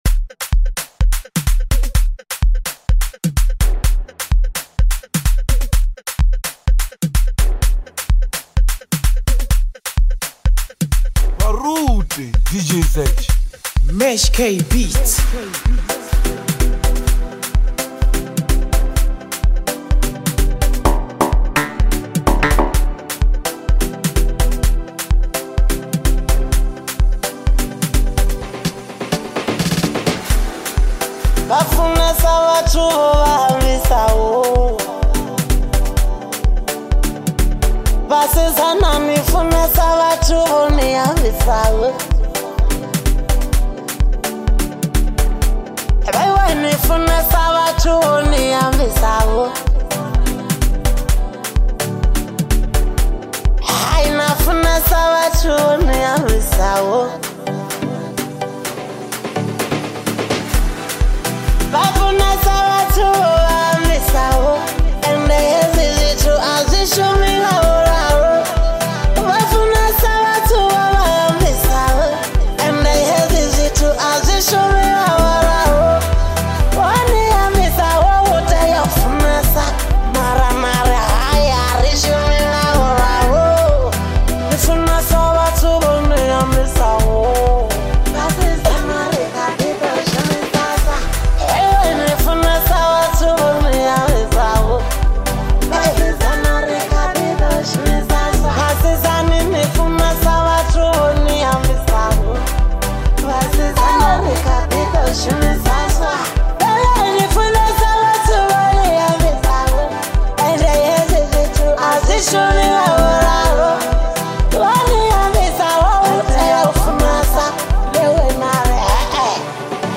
catchy rhythms